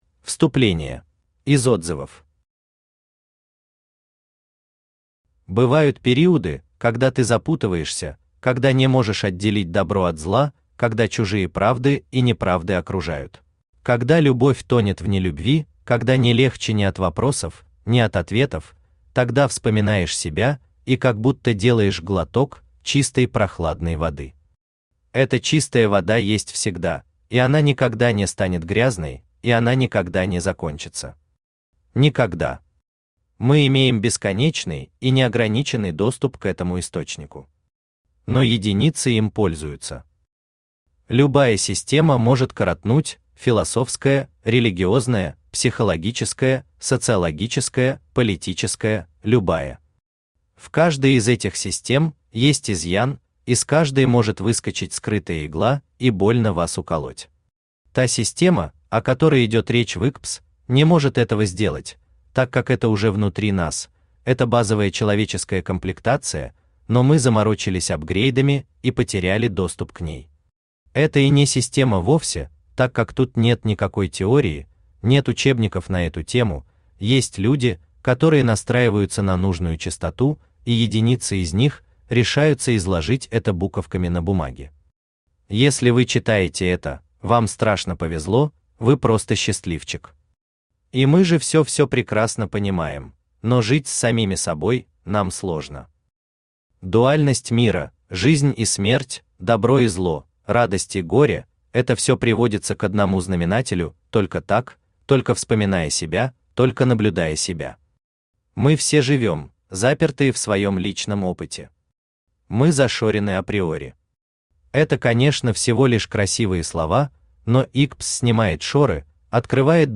Aудиокнига Интенсивный курс подготовки к свободе Автор Виталий Зегмантович Лозовский Читает аудиокнигу Авточтец ЛитРес.